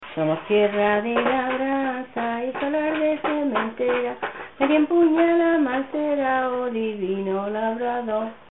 Materia / geográfico / evento: Canciones religiosas Icono con lupa
Moraleda de Zafayona (Granada) Icono con lupa
Secciones - Biblioteca de Voces - Cultura oral